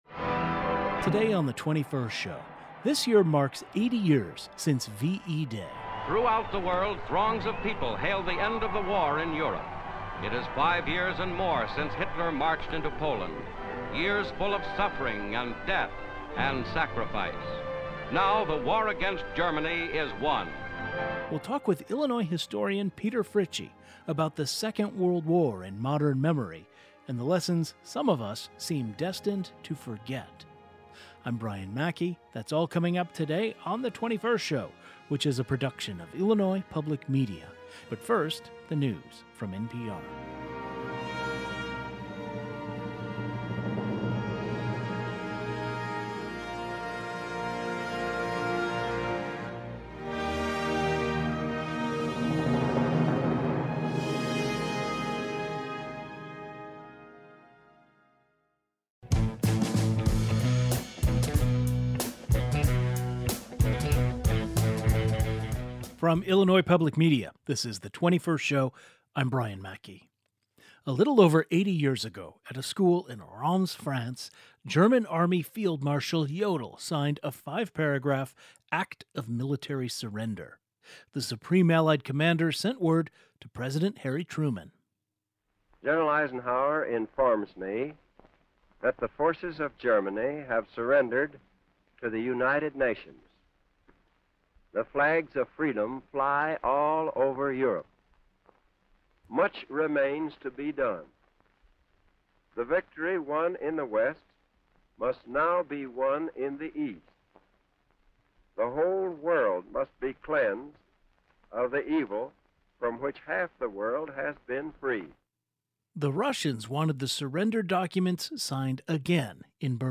A historian and author who has written about World War II joins the program to speak about VE Day 80 years later.